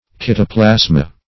Search Result for " kytoplasma" : The Collaborative International Dictionary of English v.0.48: Kytoplasma \Ky`to*plas"ma\ (k[imac]`t[-o]*pl[a^]z"m[.a]), n. [NL., fr. Gr. ky`tos a hollow vessel + pla`sma thing molded.]
kytoplasma.mp3